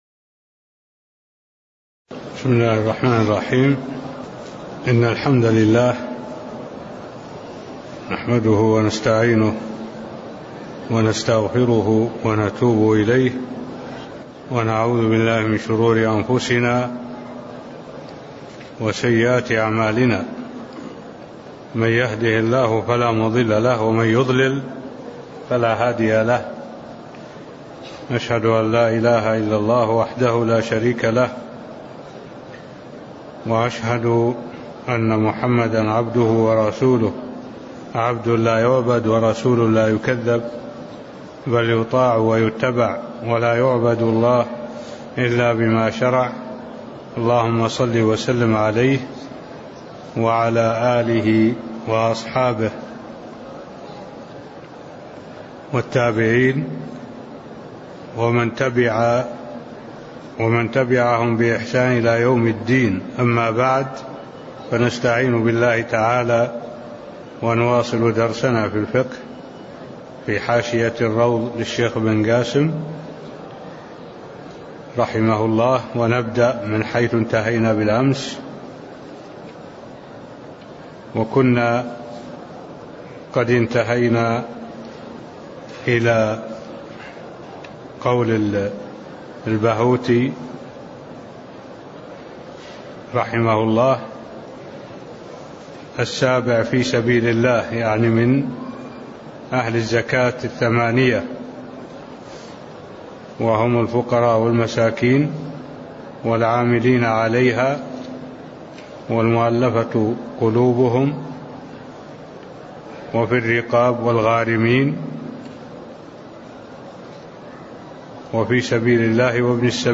تاريخ النشر ٤ جمادى الآخرة ١٤٢٩ هـ المكان: المسجد النبوي الشيخ: معالي الشيخ الدكتور صالح بن عبد الله العبود معالي الشيخ الدكتور صالح بن عبد الله العبود من السابع في سبيل الله (013)